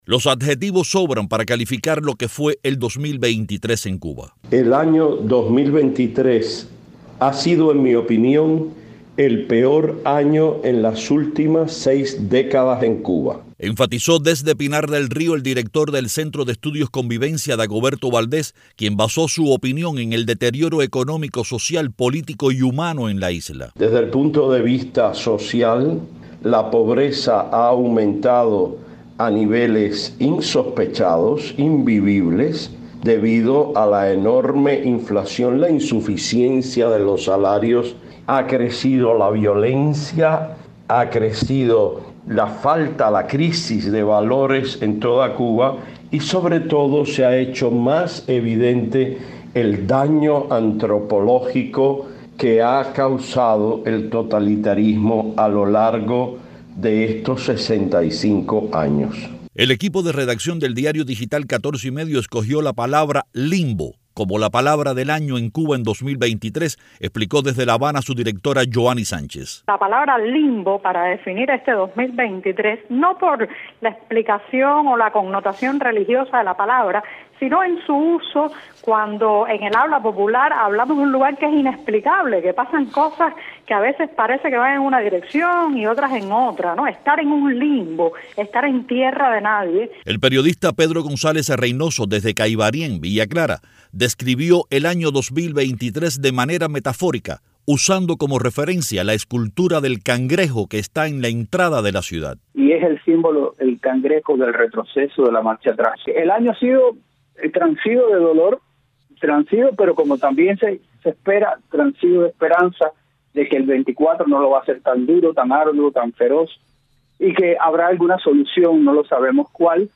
Periodistas independientes desde la isla coincidieron en que el 2023 ha sido uno de los años más difíciles que ha enfrentado el pueblo cubano en décadas. Los entrevistados por Martí Noticias se refirieron al agravamiento de la crisis económica, al éxodo sin precedentes, a la creciente falta de...